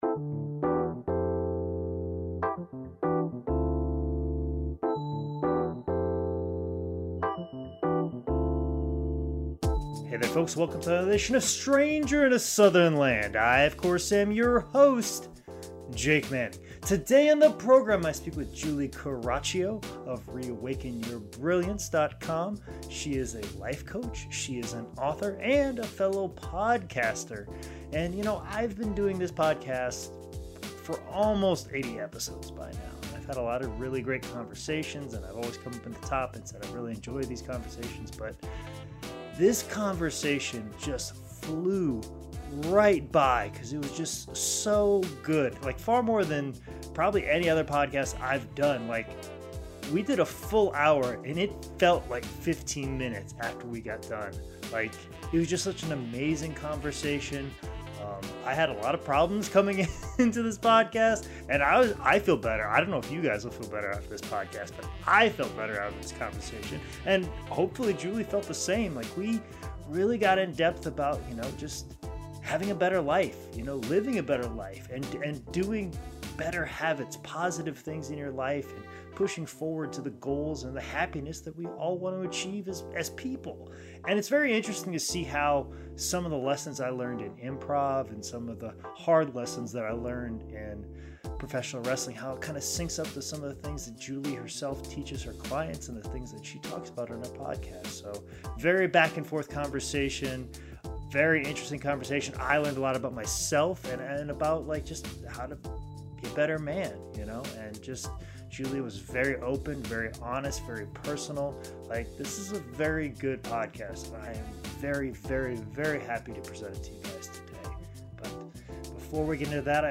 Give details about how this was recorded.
The conversation on this episode is so free flowing that the time seemed to fly by.